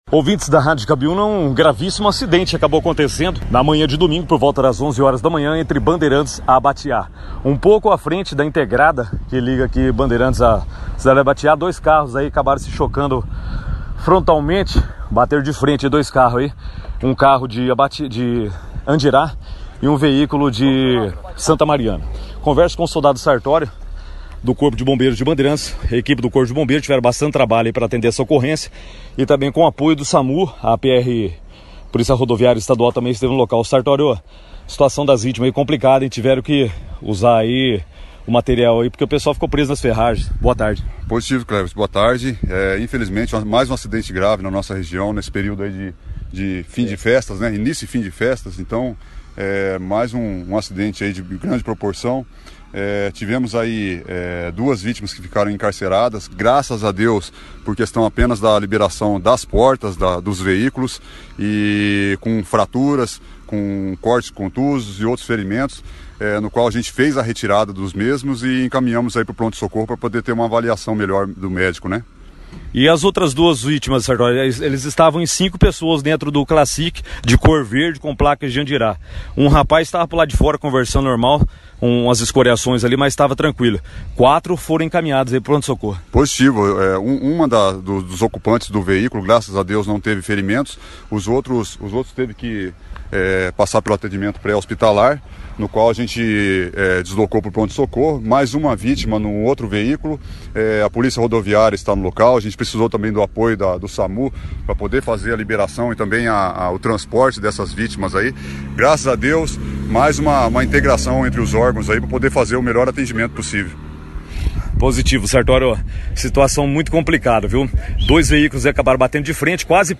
a reportagem do Jornal Operacao Cidade, da Cabiúna Fm, esteve no local